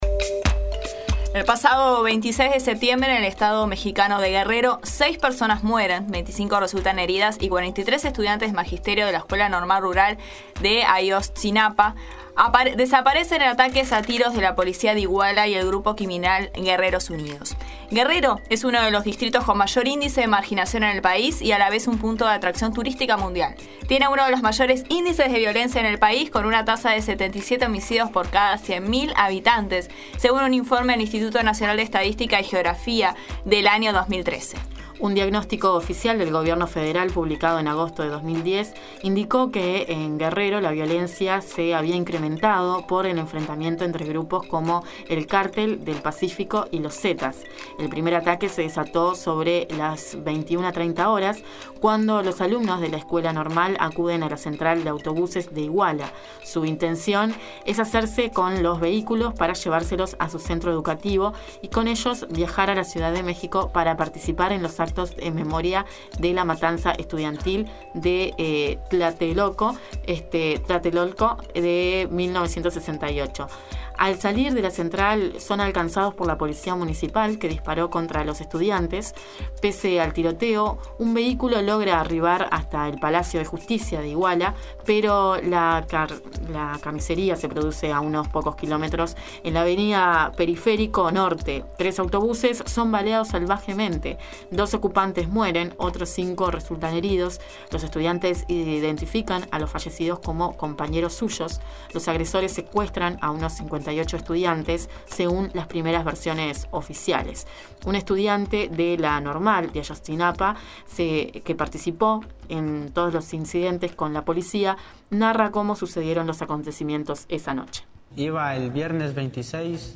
En La Nueva Mañana dialogamos con el periodista Raúl Zibechi, escritor y analista de movimientos sociales latinoamericanos de larga trayectoria, que analizó el trágico presente que vive México, las criticas al gobierno y el clamor de justicia de la región.